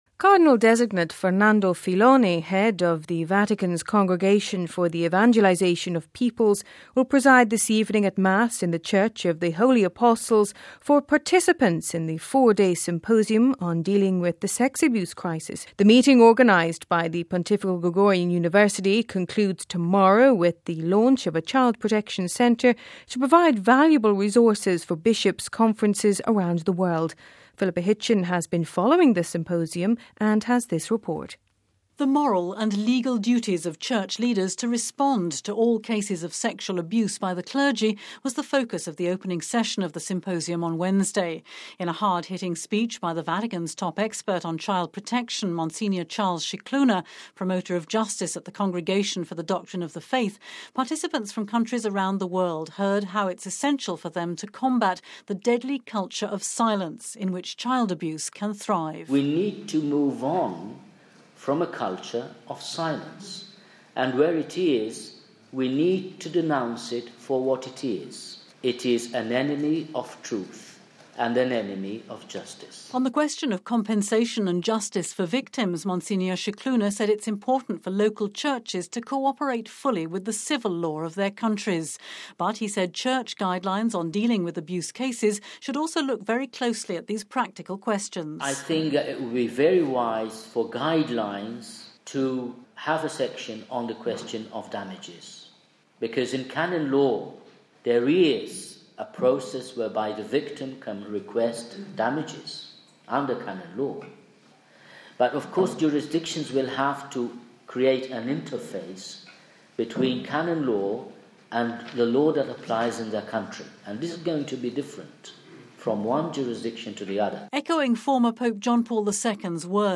In a hard hitting speech by the Vatican’s top expert on child protection, Msgr Charles Scicluna, Promoter of Justice at the Congregation for the Doctrine of the Faith, participants from countries around the world heard how it is essential for them to combat “the deadly culture of silence” which in some places still exists….